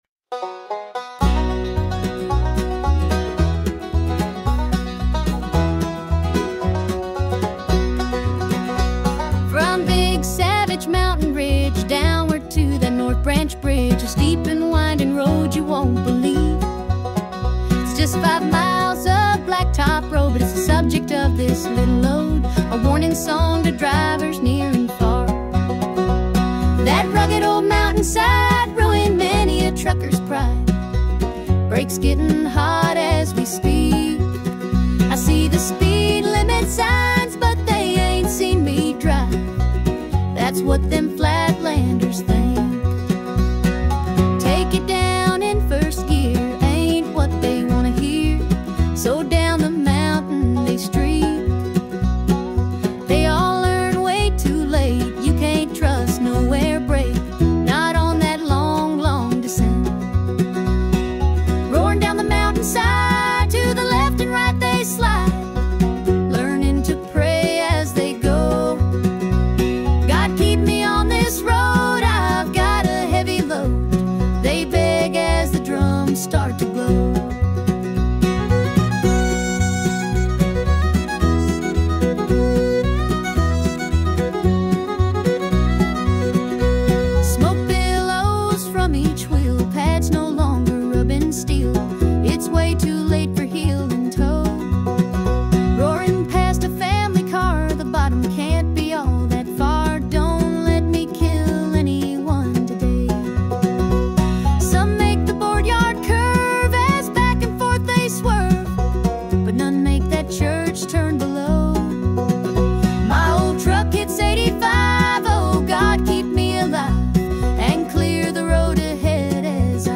Bluegrass song